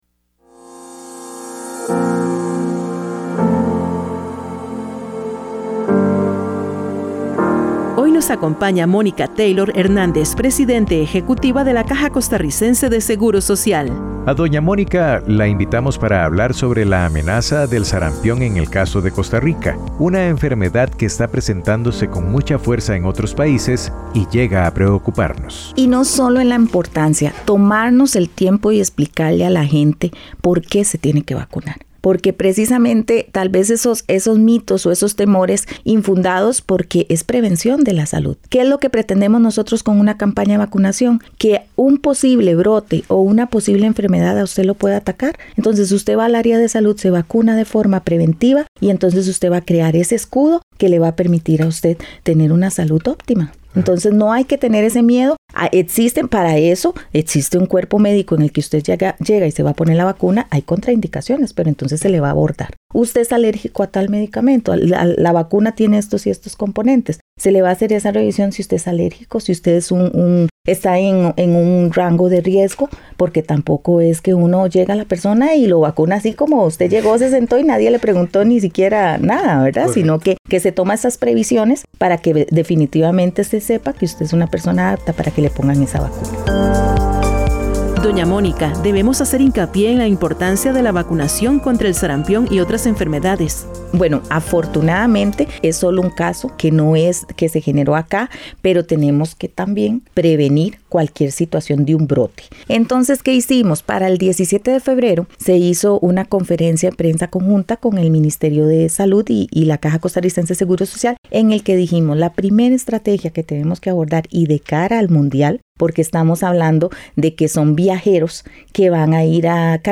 Entrevista a Mónica Taylor, presidente ejecutiva de la CCSS